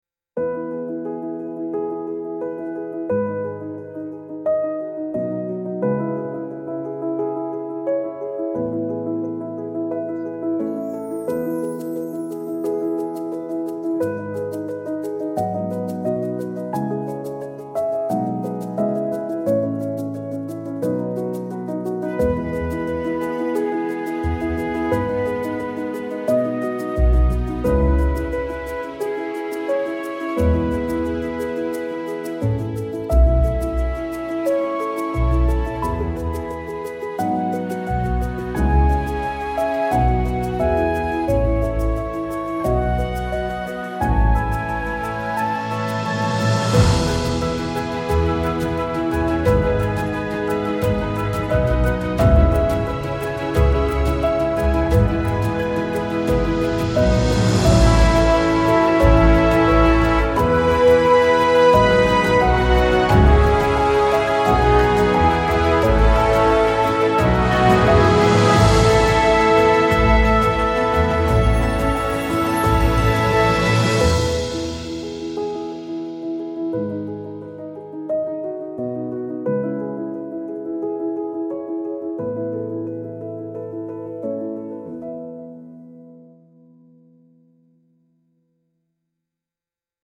life-affirming warm orchestral piece with hopeful melody and light percussion